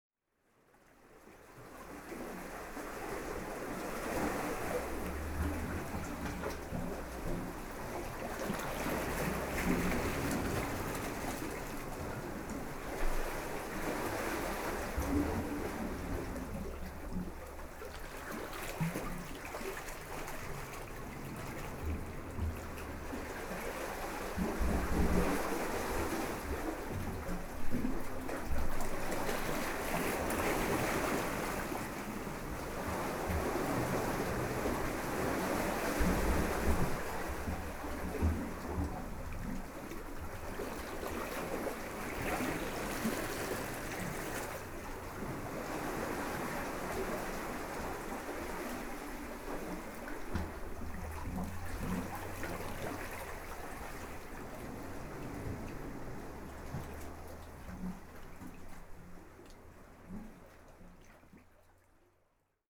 seasound.wav